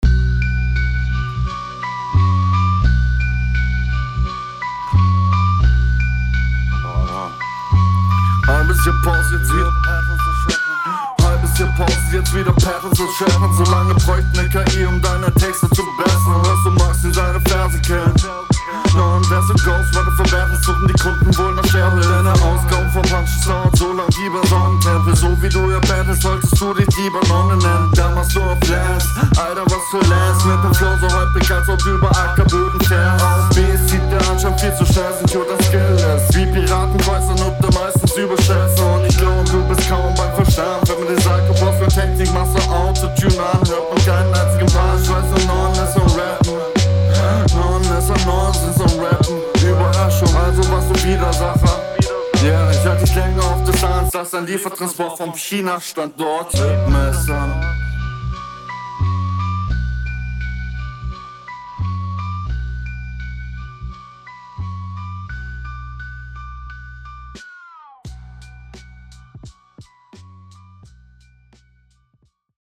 Leider ist es sehr schwer dir akustisch zu folgen.
Leicht unverständlich, rate dir deine zweite Spur etwas leiser zu mischen.